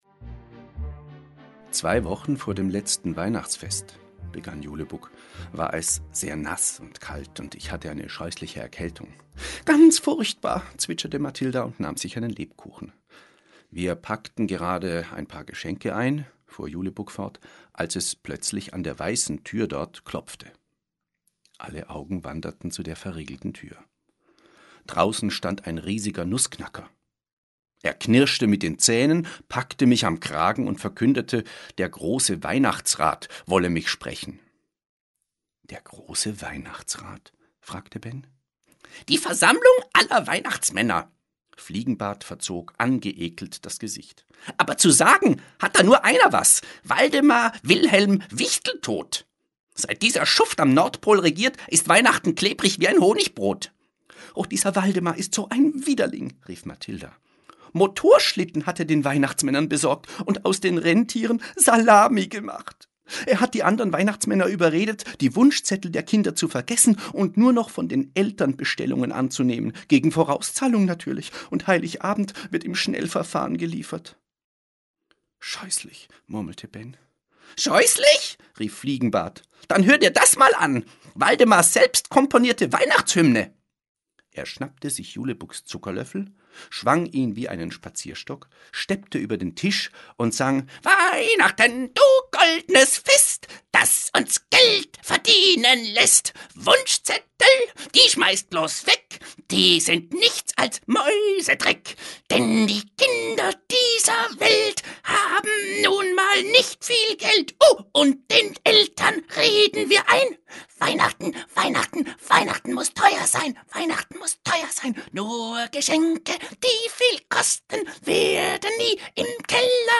Schlagworte Advent • Hörbuch für Kinder/Jugendliche • Hörbuch für Kinder/Jugendliche (Audio-CD) • Hörbuch; Lesung für Kinder/Jugendliche • Weihnachten • Weihnachten; Kinder-/Jugendlit.